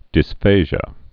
(dĭs-fāzhə, -zhē-ə)